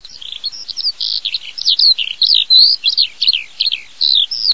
L'Allodola (Alauda arvensis) è un grazioso uccello dal piumaggio fortemente mimetico; di solito si trova, specie in autunno - inverno, nei campi coltivati, nei prati e nelle zone collinari aperte.
canta (98 KB) mentre compie un caratteristico volo librato circolare.